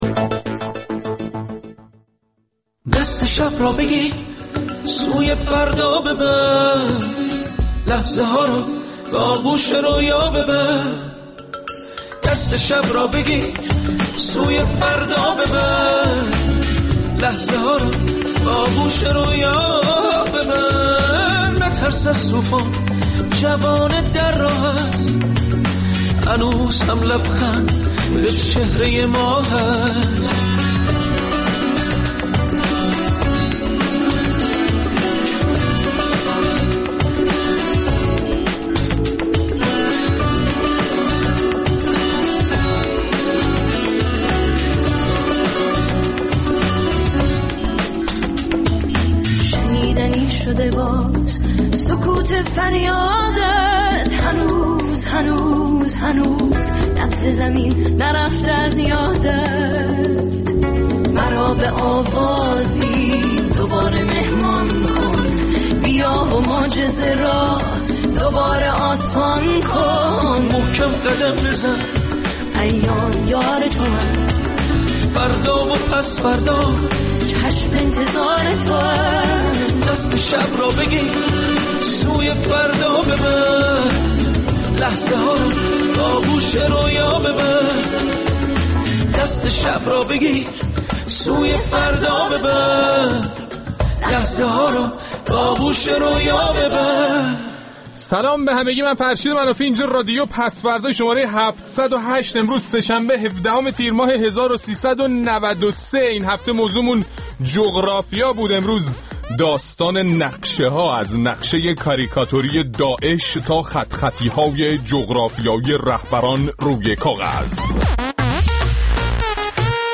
رادیو پس‌فردا برنامه‌ای است در رادیو فردا که از شنبه تا چهارشنبه به مدت یک ساعت از ۲۱:۰۰ تا ۲۲:۰۰ شب به وقت ایران با اجرای فرشید منافی با زبان طنز آزاد به مسائل سیاسی، اجتماعی، اقتصادی، هنری و حتی ورزشی روز ایران و جهان می‌پردازد.